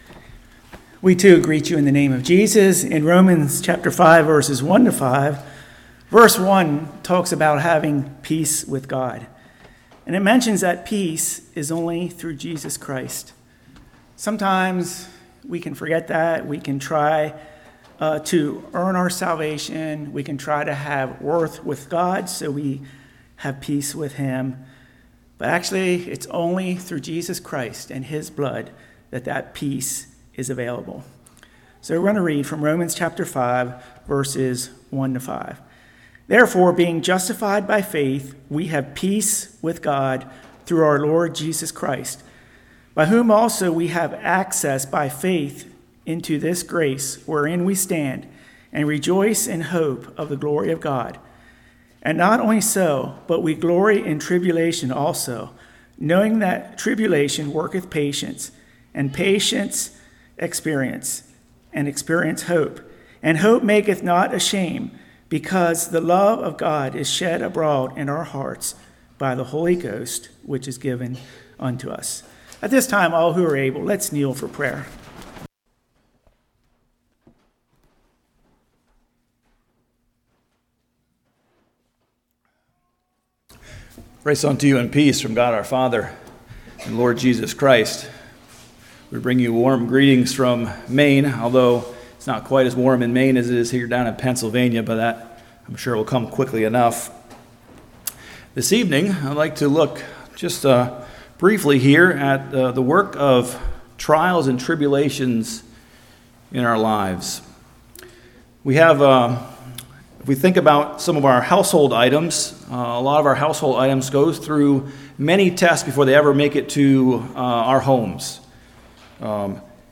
Service Type: Love Feast